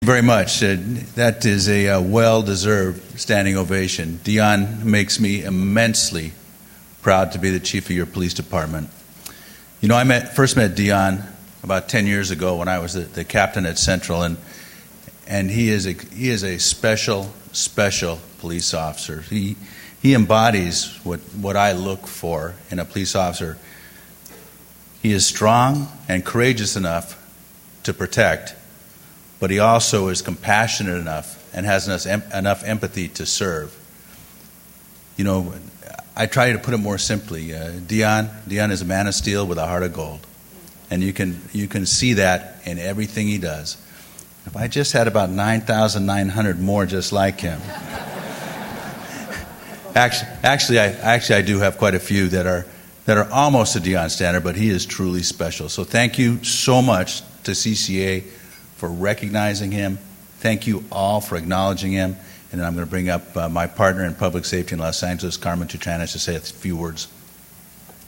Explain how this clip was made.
The banquet was held at the Westin Bonaventure Hotel located in Downtown Los Angeles.